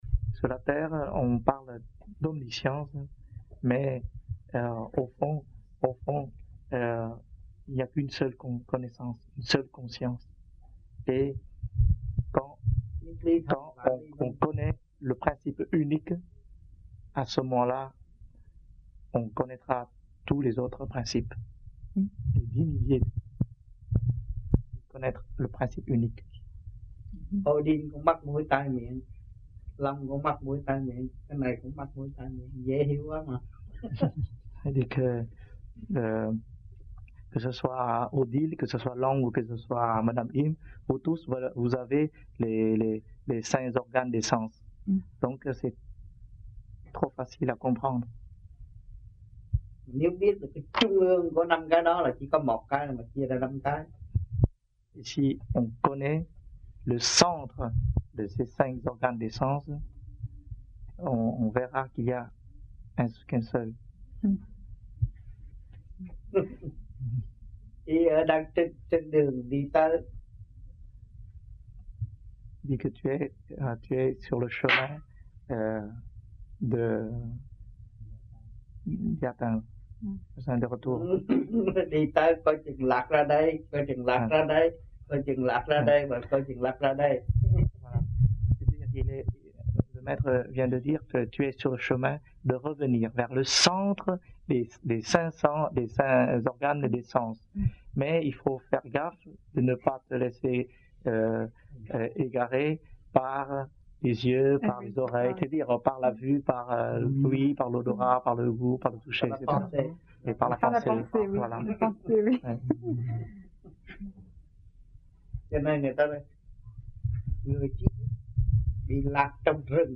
1980-11-20 - AMPHION - THUYẾT PHÁP 04